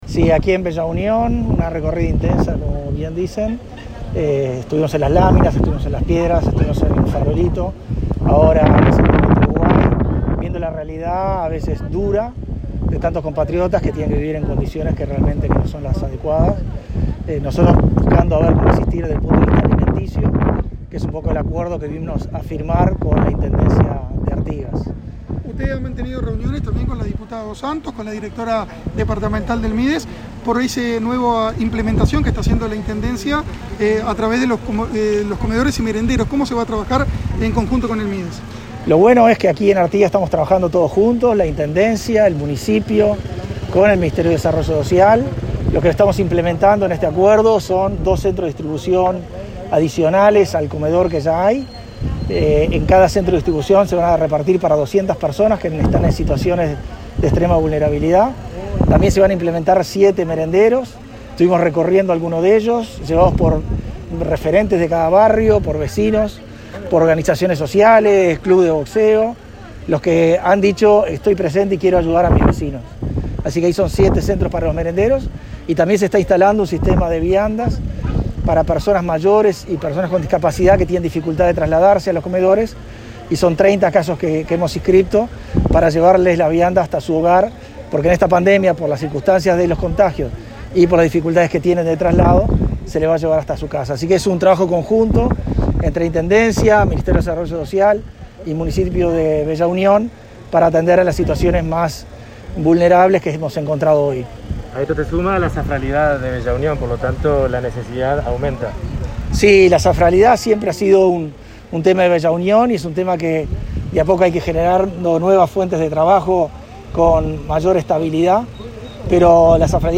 Declaraciones del ministro de Desarrollo Social, Pablo Bartol
En declaraciones a la prensa, el 14 de enero, Bartol destacó la recorrida realizada por Bella Unión, en la cual se firmó un acuerdo con la Intendencia